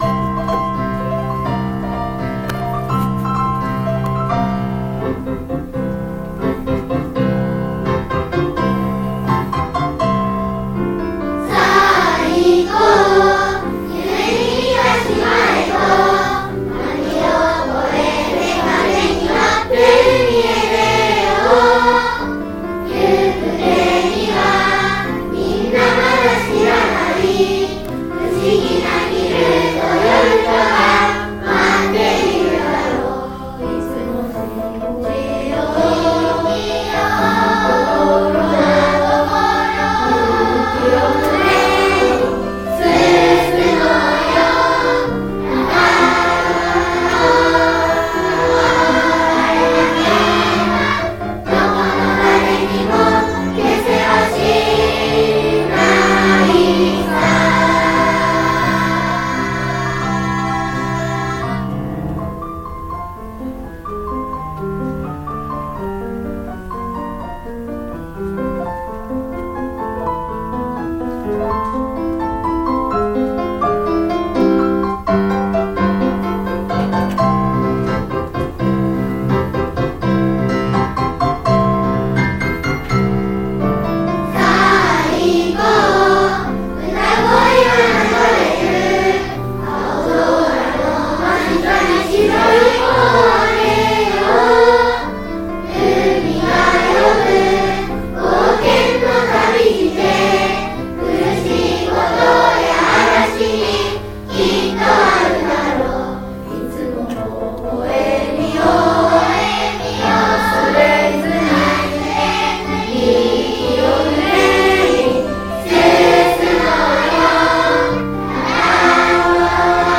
全校合唱